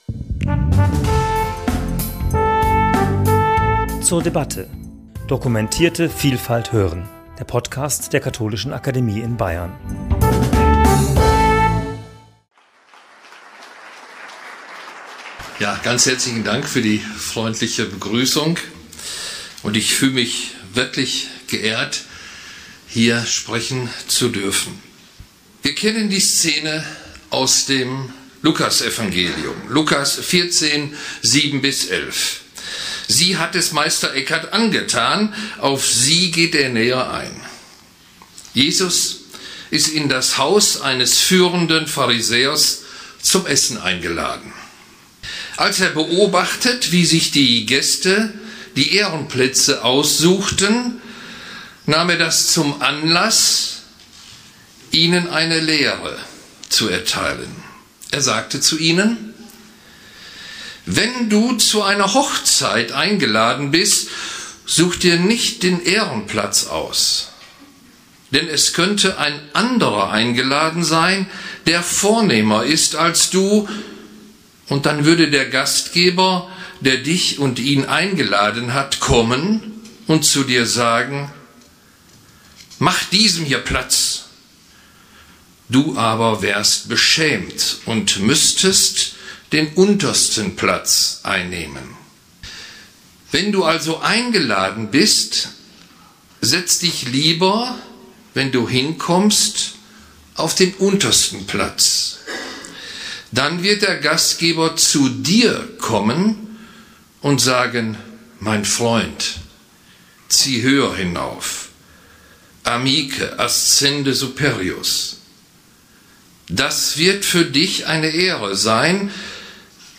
Die Jahrestagung der Meister-Eckhart-Gesellschaft zum 20. Jubiläum ihrer Gründung im Jahr 2004, zum siebten Mal schon in Zusammenarbeit mit der Katholischen Akademie in Bayern, ist offen für alle Interessierten.